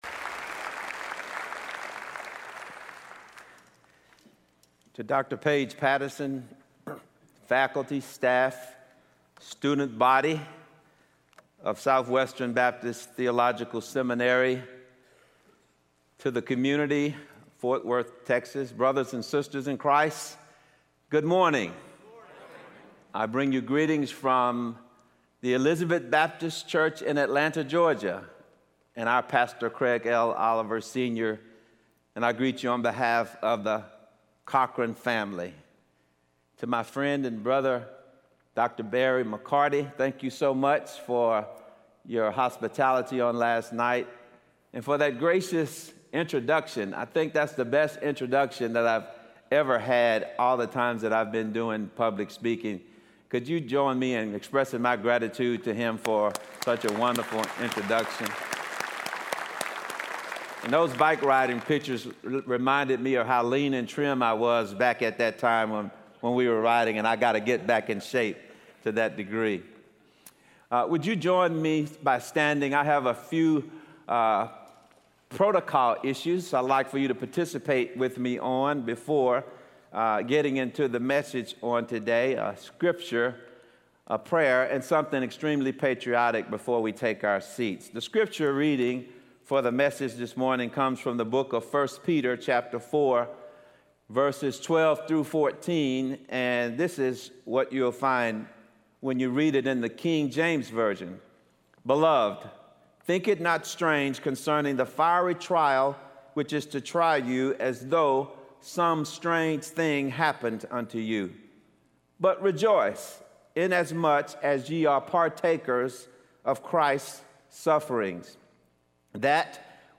Kelvin Cochran speaking on I Peter in SWBTS Chapel on Thursday October 29, 2015
SWBTS Chapel Sermons Kelvin Cochran - The Blessing of Suffering Play Episode Pause Episode Mute/Unmute Episode Rewind 10 Seconds 1x Fast Forward 30 seconds 00:00 / Subscribe Share RSS Feed Share Link Embed